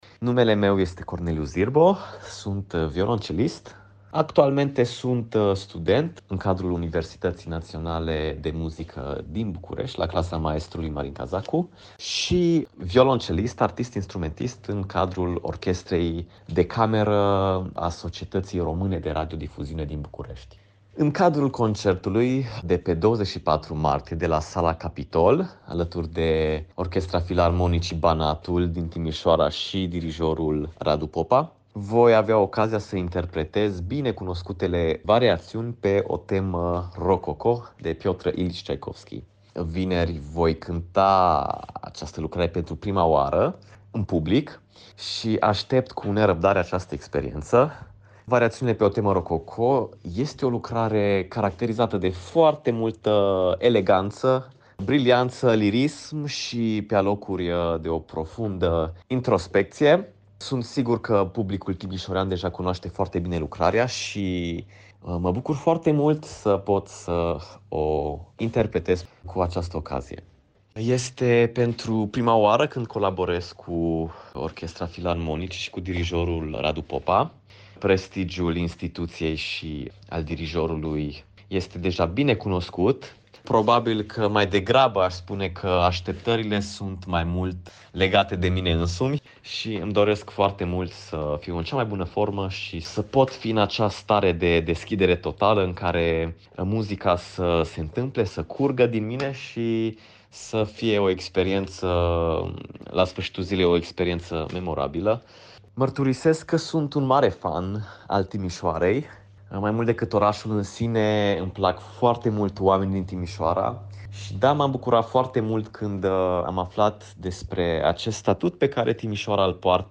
interviu Radio Timișoara